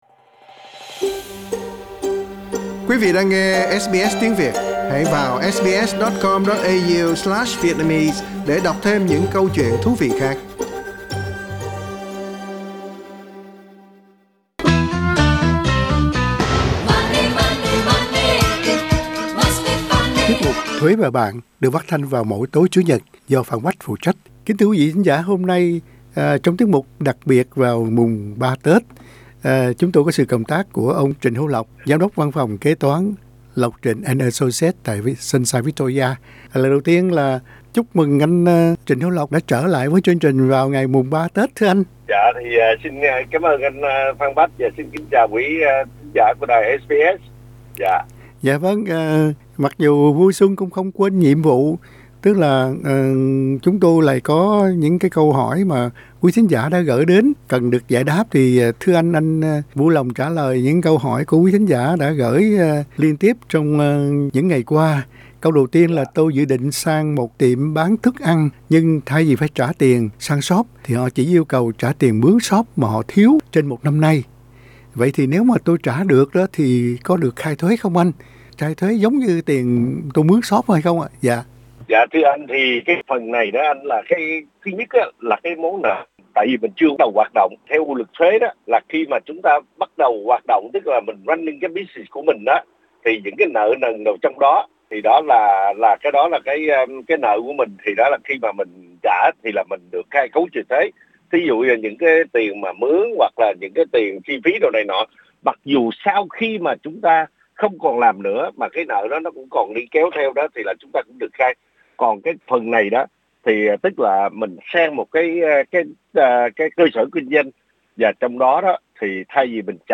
Tiết mục Thuế Và Bạn xin kính chúc quí thính giả nhiều sức khoẻ và tiền tài tràn đầy, như bản nhạc Money Money dẫn đầu chương trình.